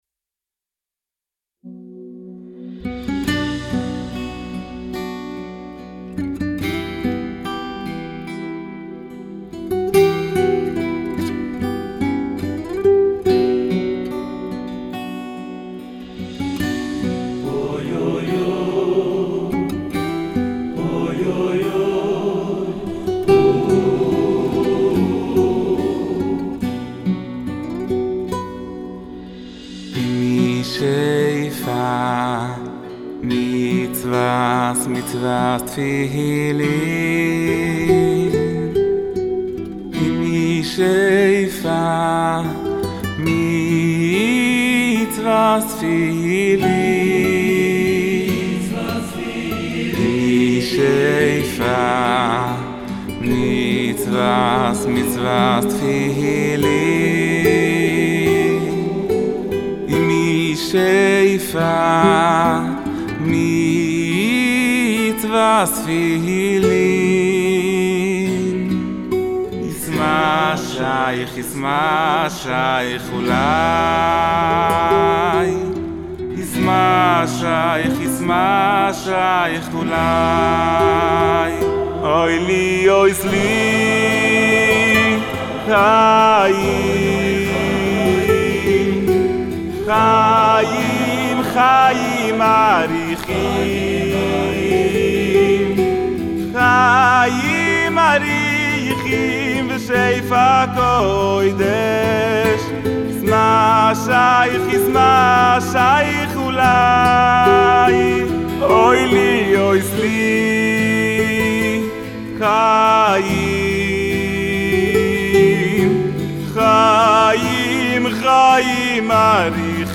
מקהלות